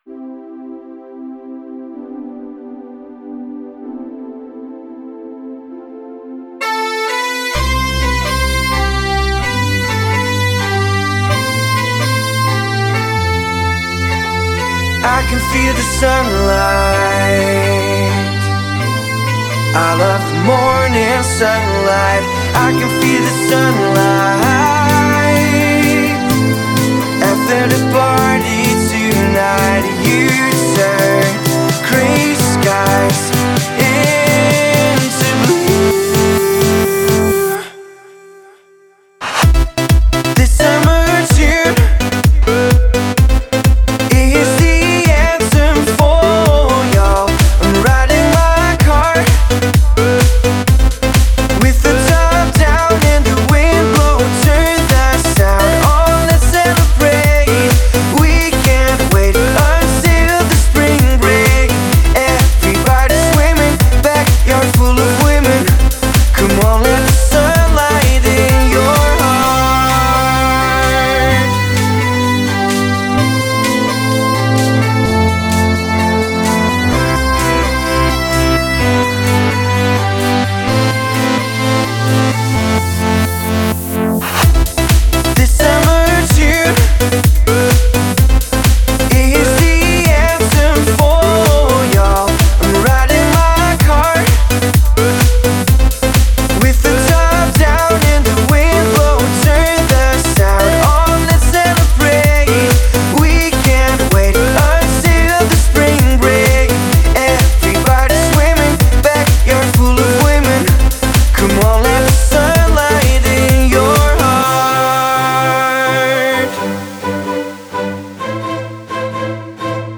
Жанр: Клубняк, размер 6.82 Mb.